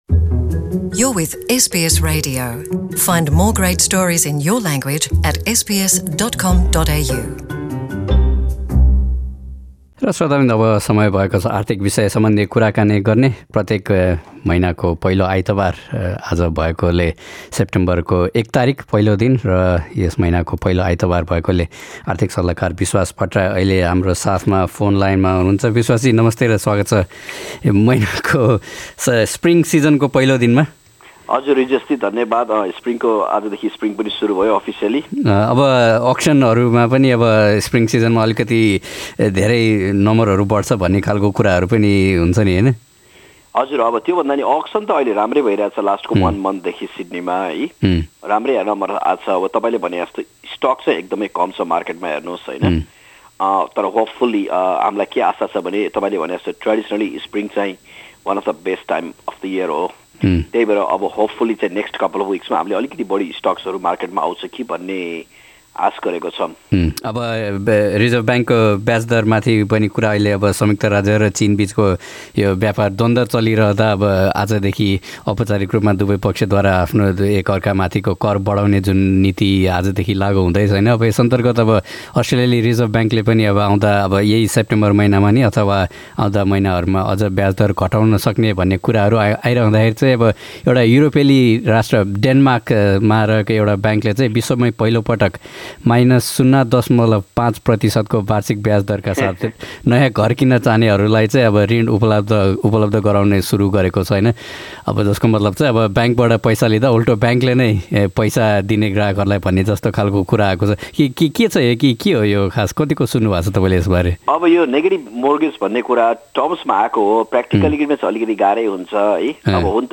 आर्थिक सल्लाहकार